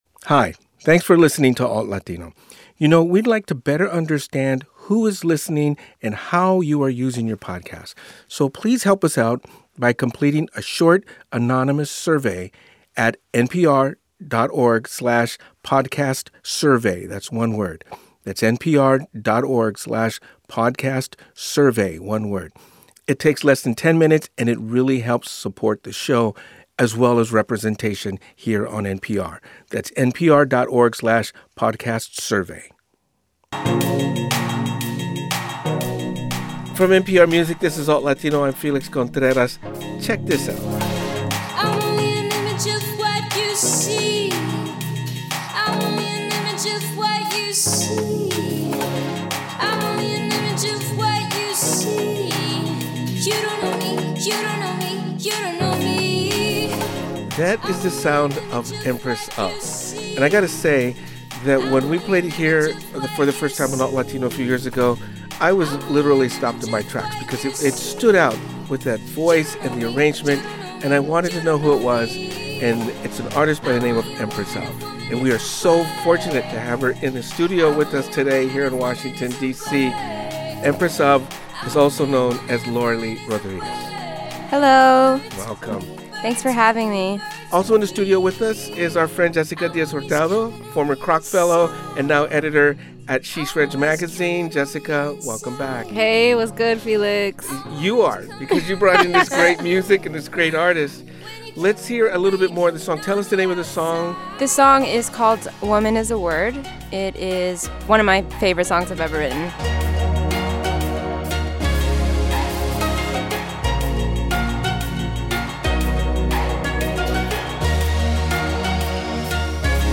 Guest DJ: Empress Of Plays Sing-Along With Some Of Her Greatest Influences